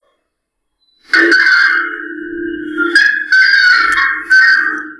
coscoroba.wav